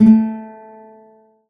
guitar_a.ogg